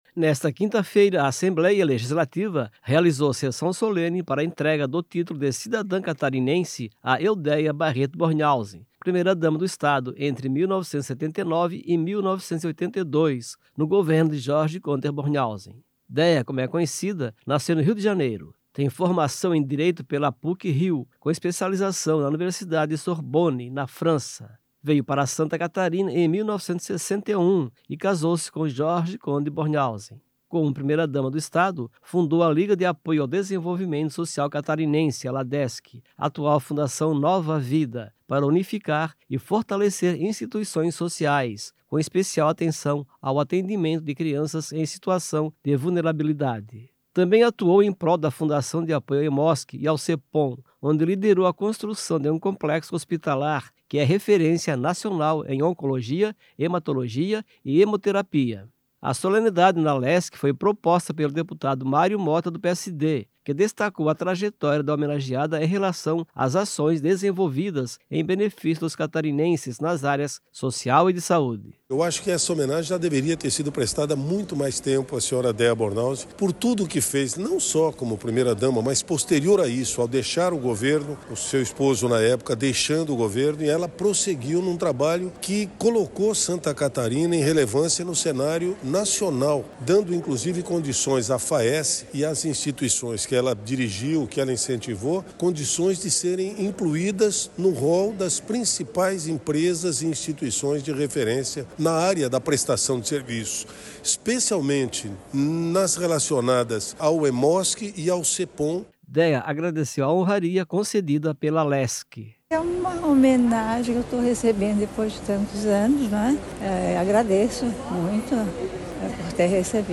Nesta quinta-feira (13), a Assembleia Legislativa realizou sessão solene para a entrega do título de cidadã catarinense a Eudéa Barreto Bornhausen, primeira-dama do Estado entre 1979 e 1982, no governo de Jorge Konder Bornhausen
Entrevistas com:
- deputado Mário Motta (PSD);